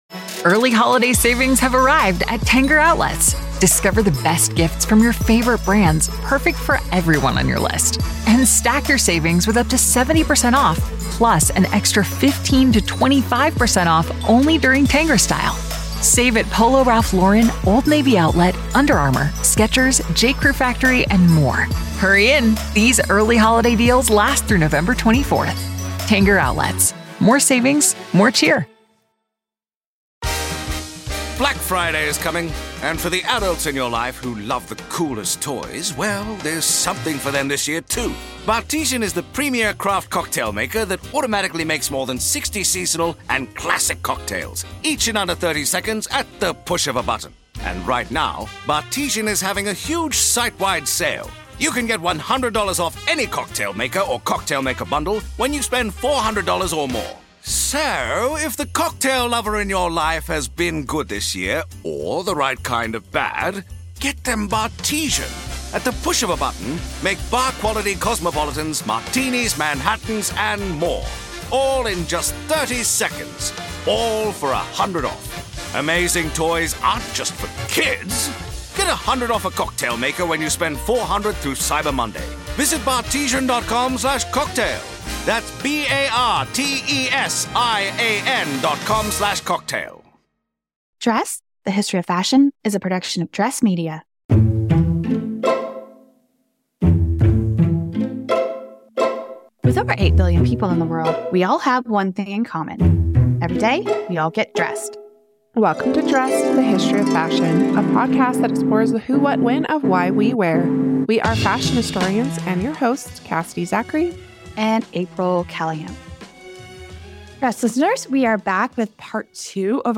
"It's Got Pockets!": Gender and Pocket Parity, an interview